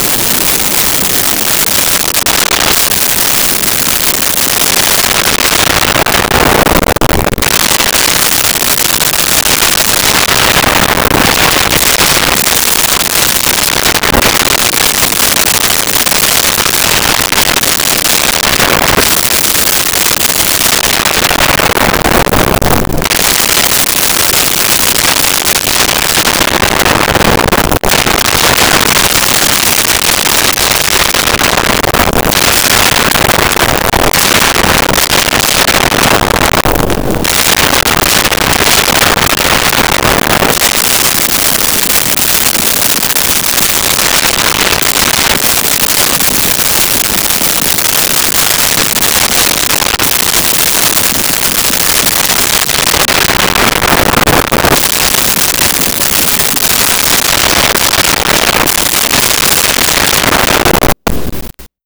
Fireworks Distant
Fireworks Distant.wav